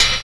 HH 29.wav